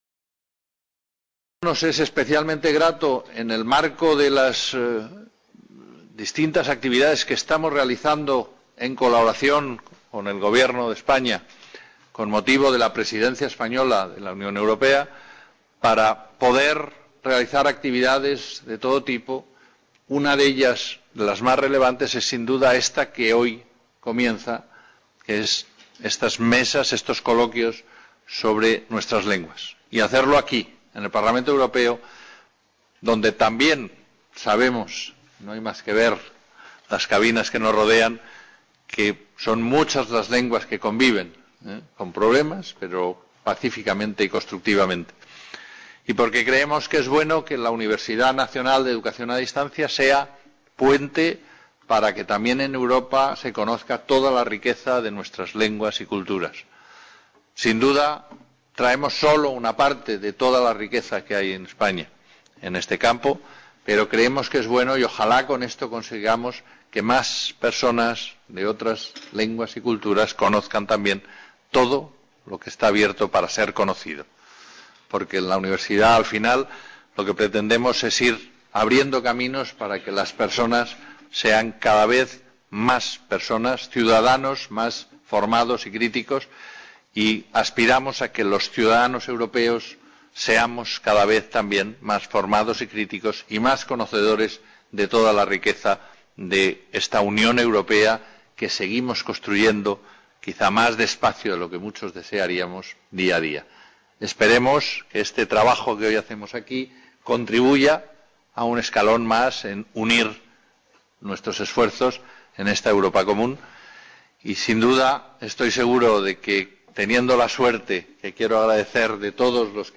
. Marifé de Santiago Bolaños, Directora General de Educación y Cultura del Gabinete de la Presidencia del Gobierno de España
. María Badia i Cuchet, Diputada del Parlamento Europeo, Responsable de la Comisión de Cultura